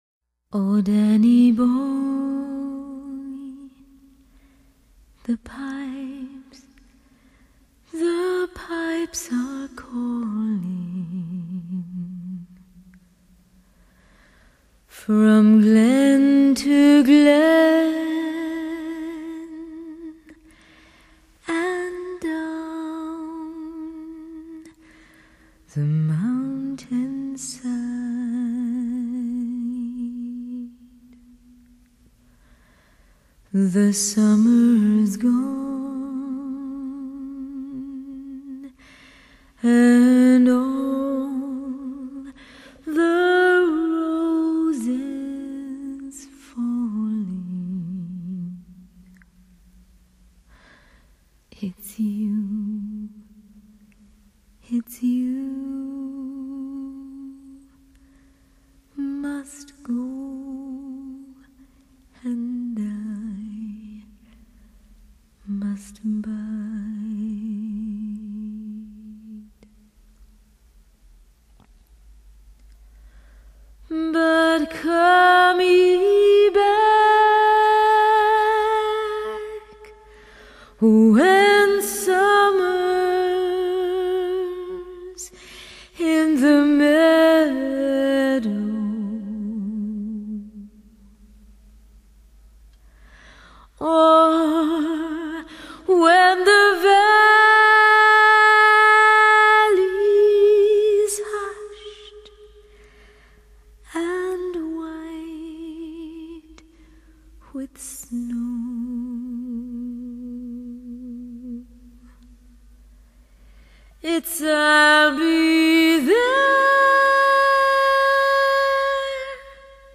A Reference Collection of Female Vocalists